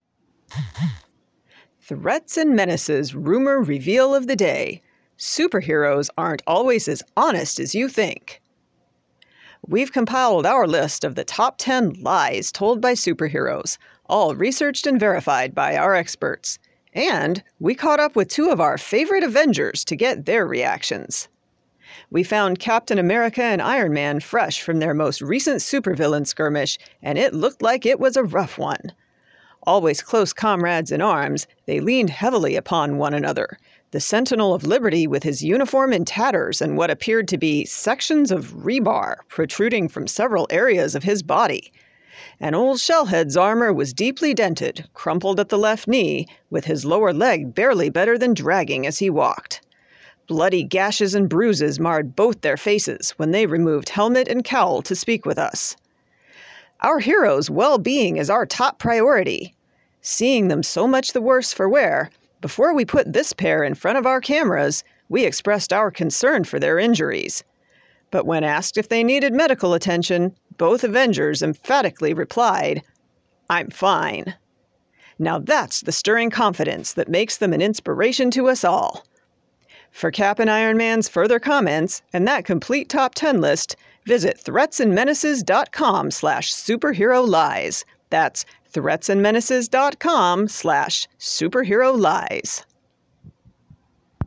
Work Type: Podfic (1:34)
I find her speaking cadence and inflections to be distinct and effective with a nice dry humor, and I did my best to channel my interpretation of how she might have delivered this piece.